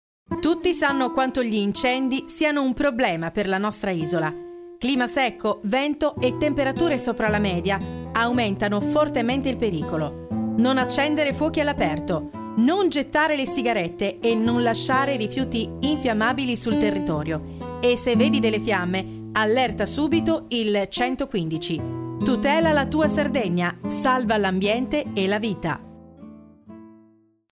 Spot campagna sensibilizzazione "Antincendio Rama 2025"